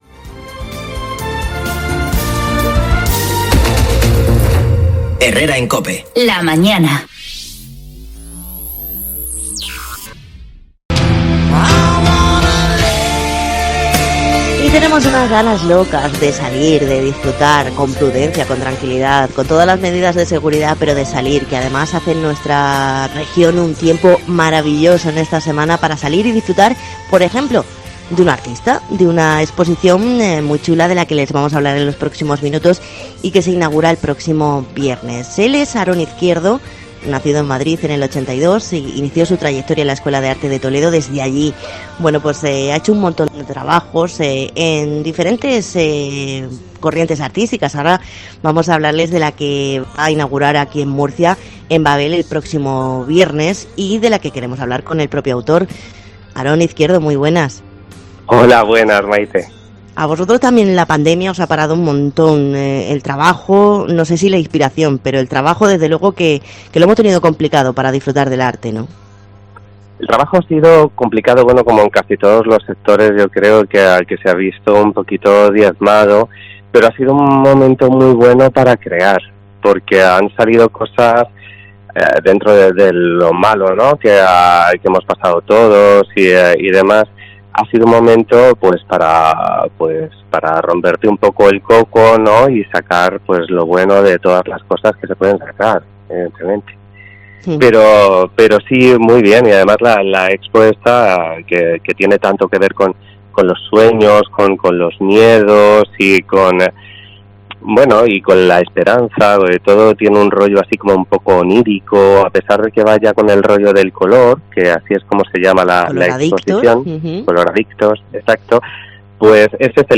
Entrevista-Cope.mp3